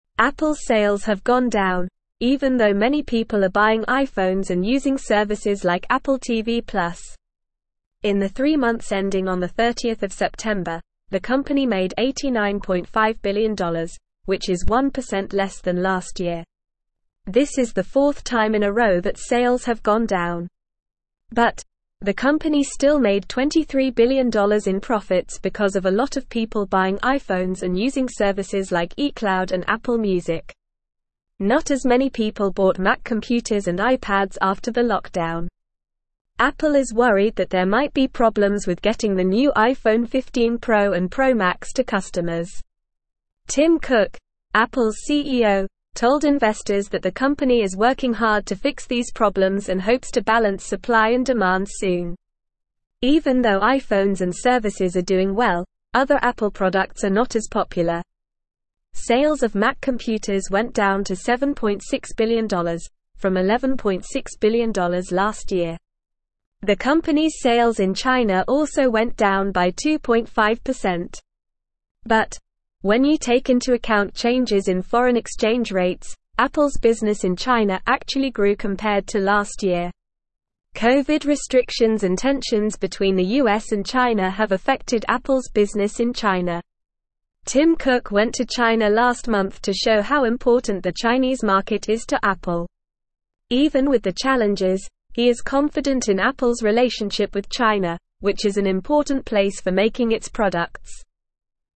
Normal
English-Newsroom-Upper-Intermediate-NORMAL-Reading-Apples-Sales-Decline-Despite-Strong-iPhone-Demand.mp3